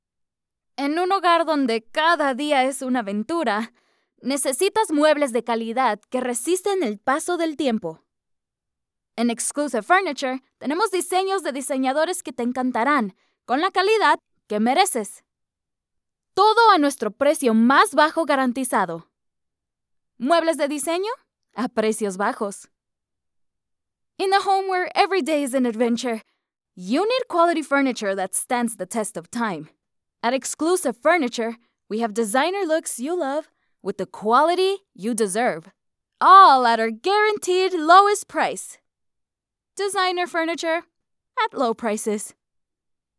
Joven, Natural, Amable
Comercial